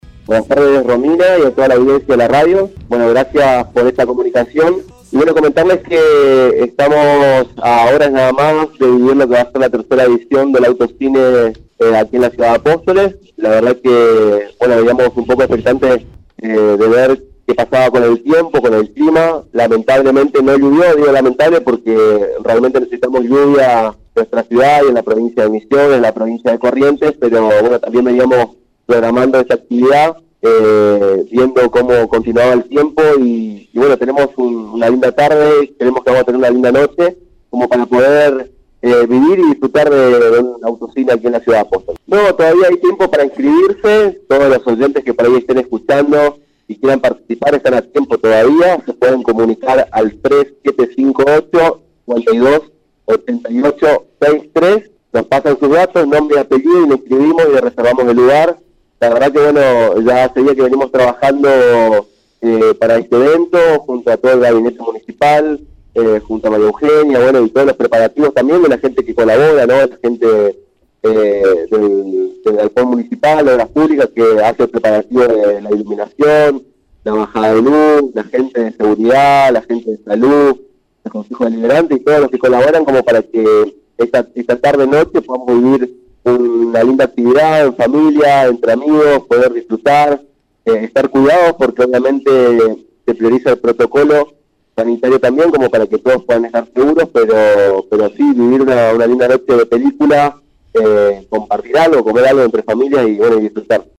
charla telefónica
Audio: Director de la Juventud, Aldo Muñoz.